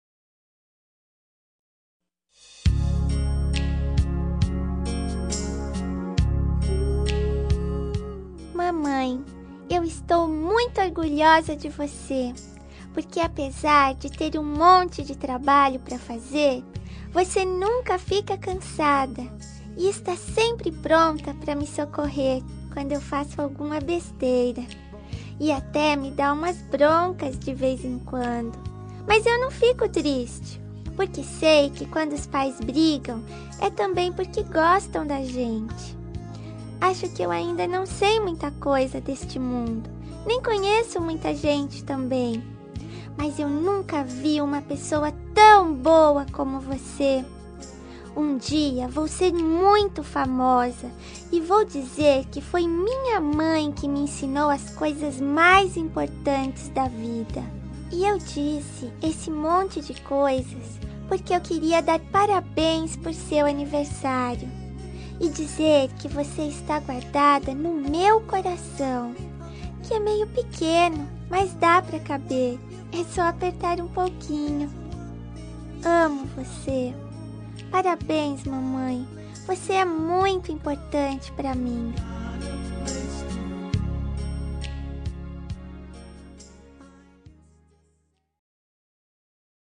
2554-infantil-mae-fem.m4a